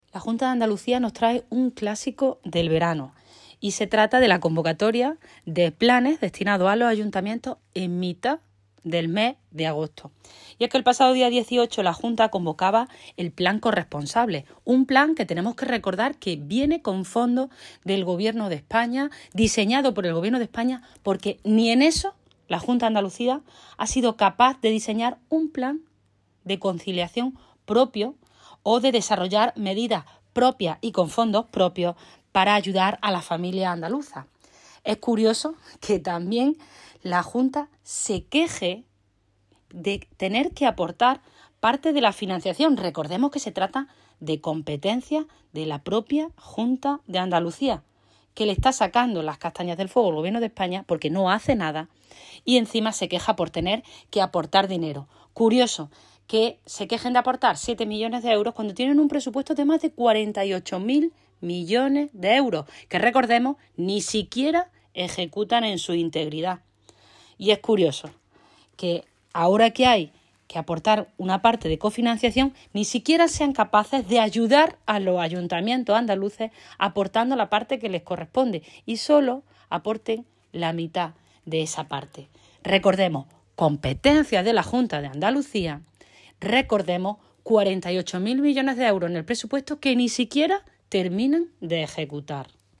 Cortes de sonido # Mercedes Gámez